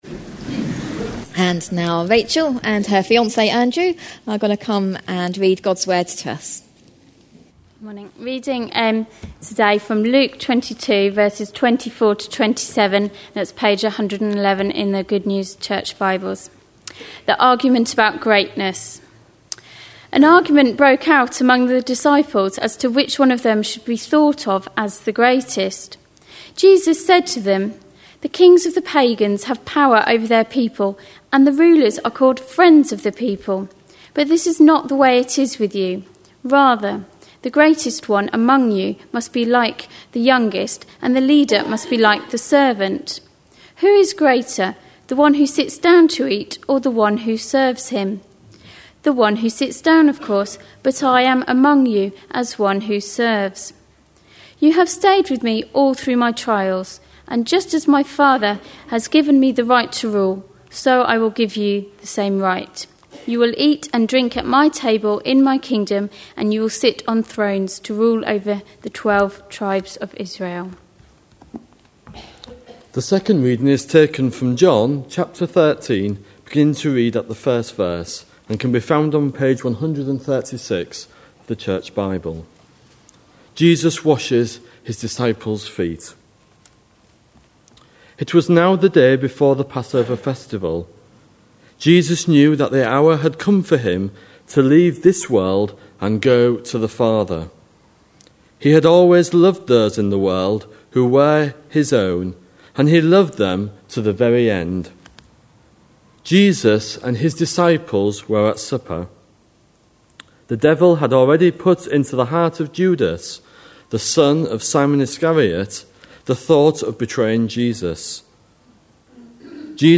A sermon preached on 6th March, 2011, as part of our A Passion For.... series.